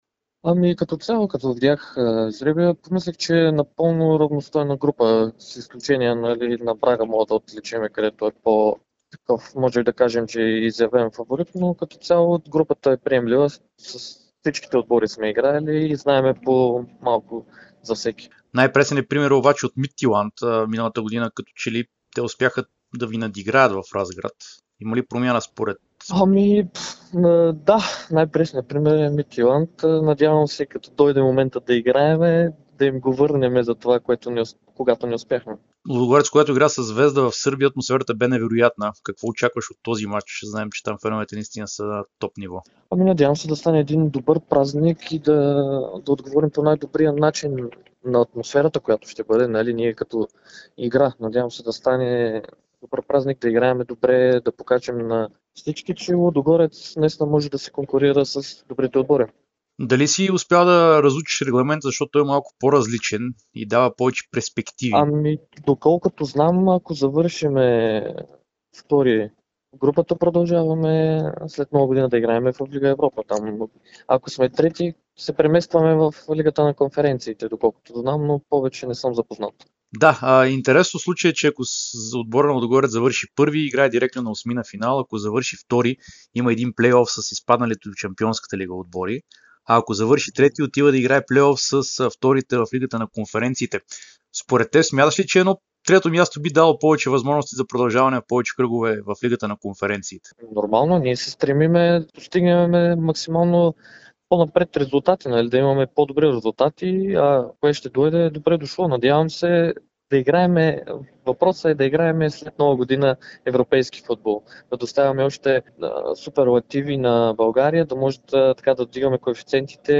Капитанът на Лудогорец Антон Недялков даде специално интервю пред Дарик радио и dsport, в което говори за жребия за груповата фаза на Лига Европа и съ... (27.08.2021 16:37:32)